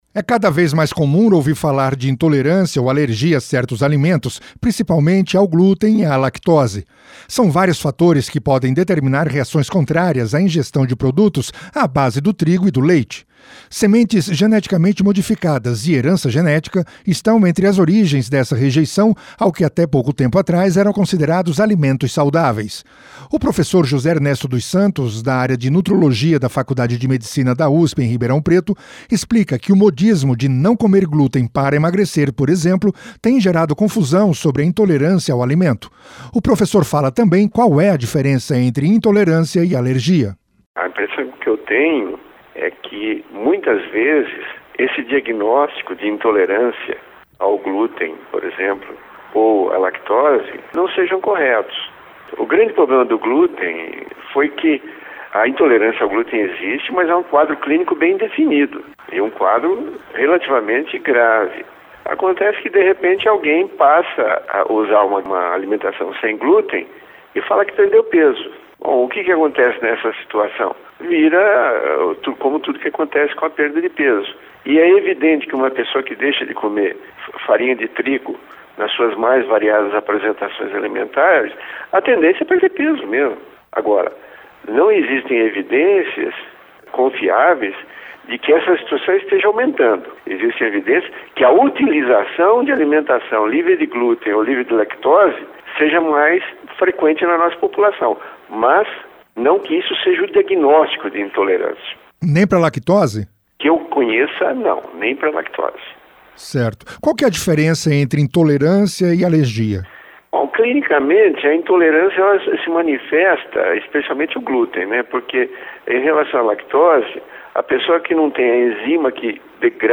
Para o professor, é preciso ter em mente que a intolerância é mais agressiva que a alergia. Ouça a entrevista no link acima.